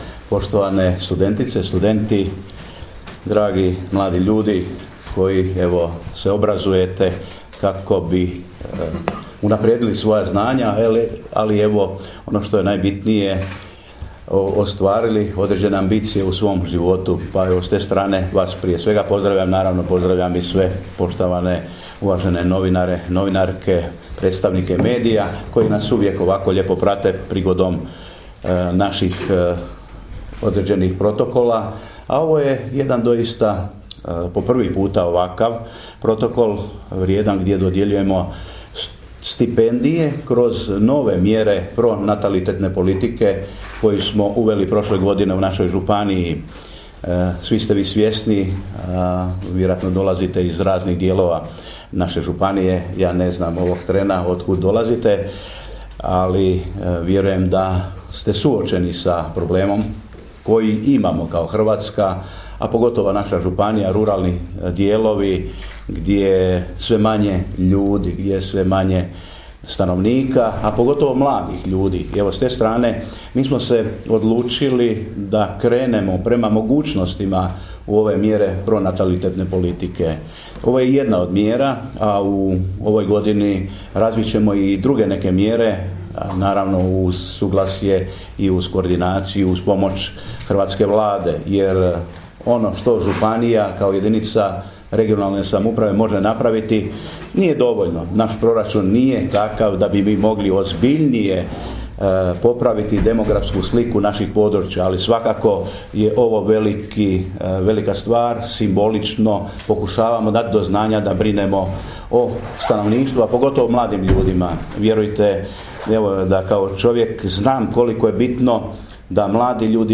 Obraćanje župana Ive Žinića studentima-stipendistima možete poslušati ovdje: